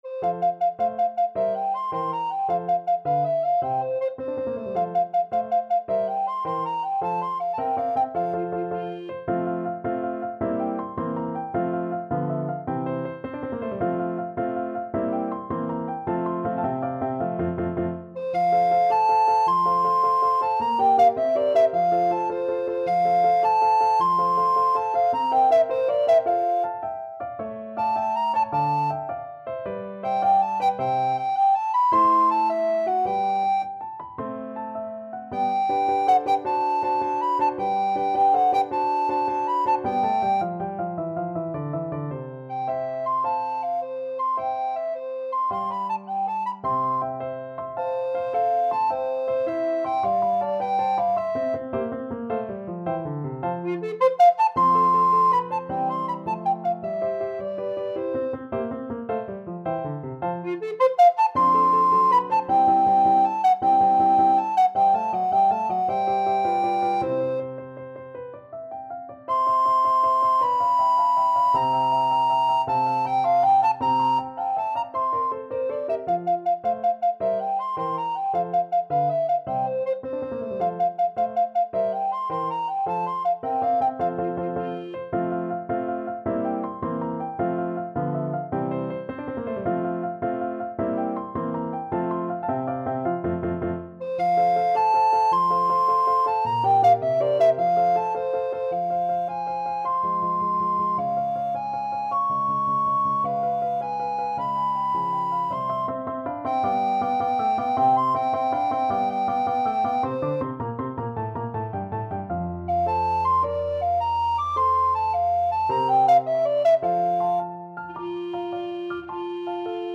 6/8 (View more 6/8 Music)
.=106 Allegro vivace (View more music marked Allegro)
Alto Recorder  (View more Advanced Alto Recorder Music)
Classical (View more Classical Alto Recorder Music)